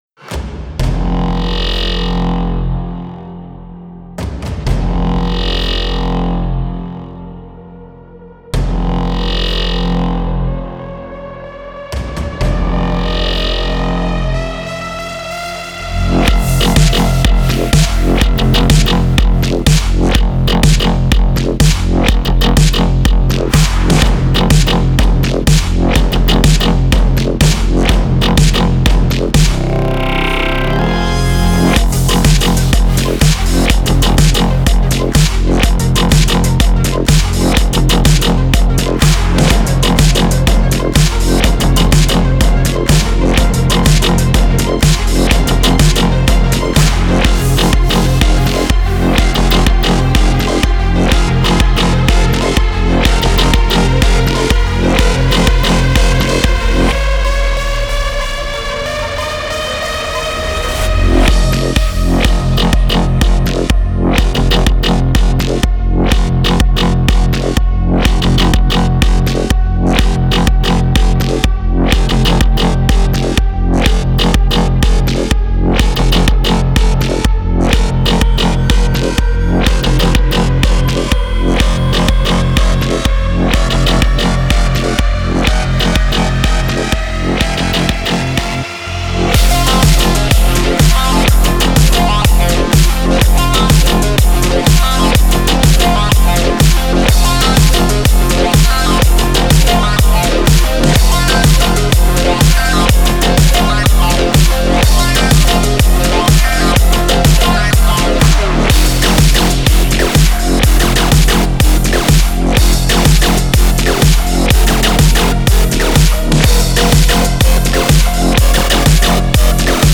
آهنگ بی کلام